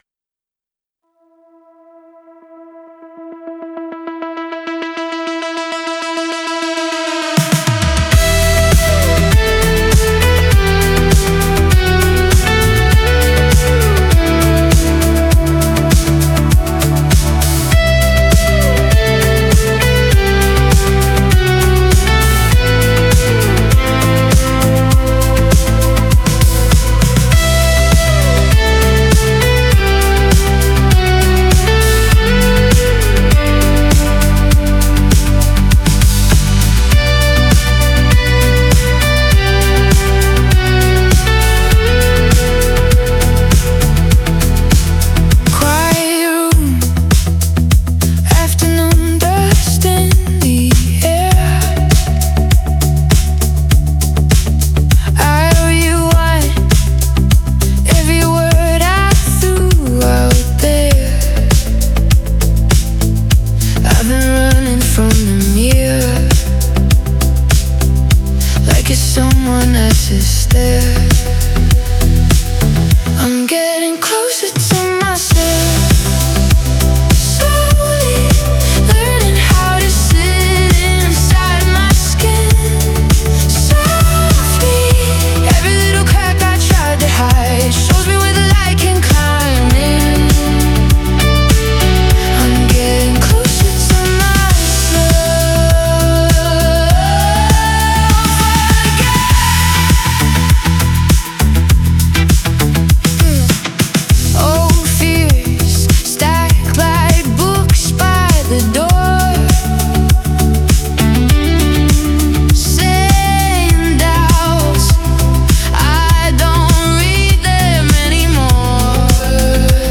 This is a cover/live version of my bro's
Melodic
Recorded in Ableton Live.
main solo guitar & bass.